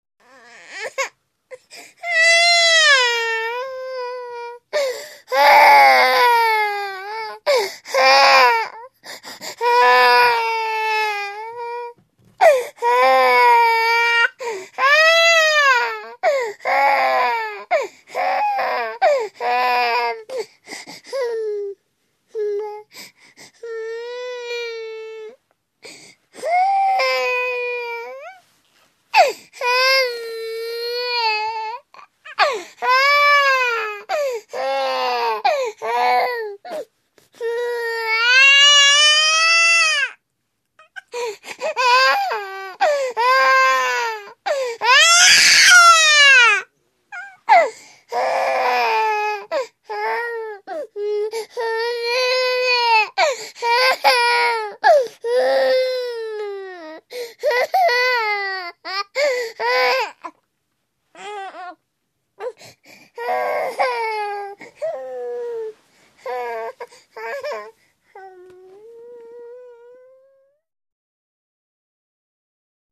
15newborn.mp3